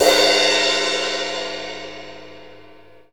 CYM CRASHS05.wav